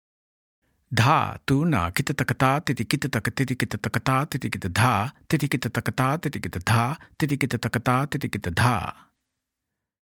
Demonstrations
Spoken